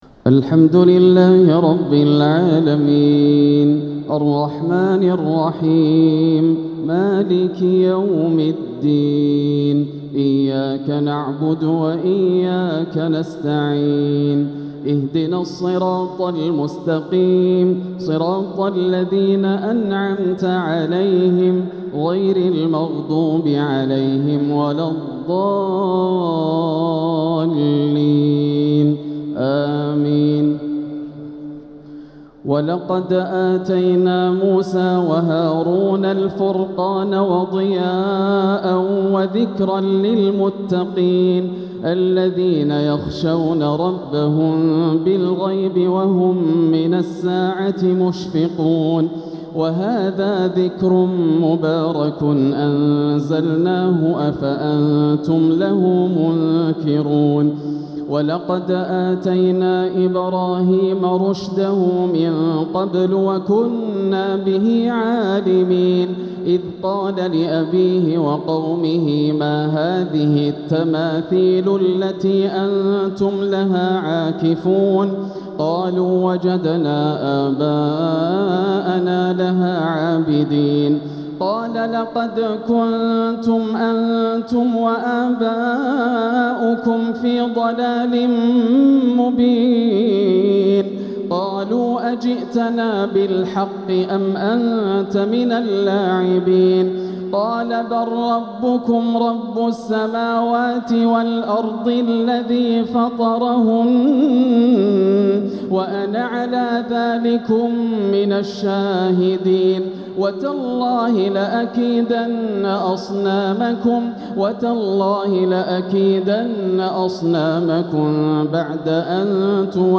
تهجد ليلة 21 رمضان 1446هـ من سورتي الأنبياء (48-112) و الحج (1-16) > الليالي الكاملة > رمضان 1446 هـ > التراويح - تلاوات ياسر الدوسري